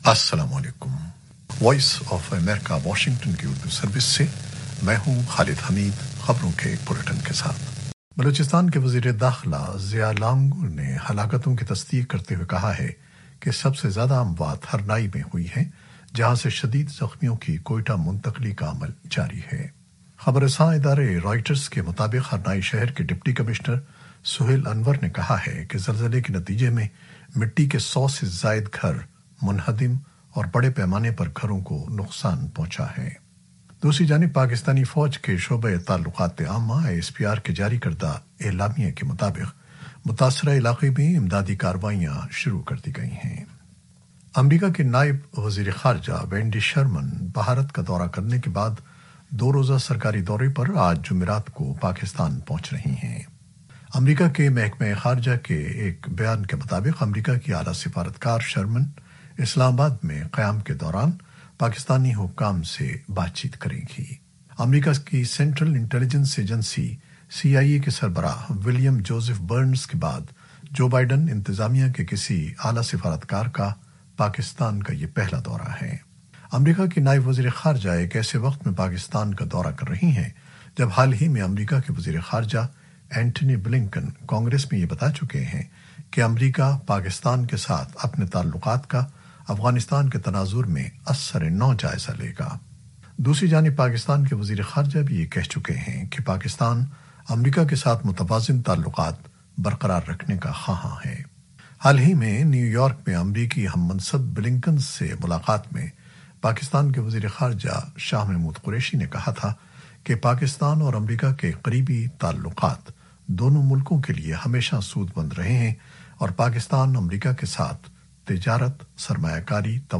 نیوز بلیٹن 2021-07-10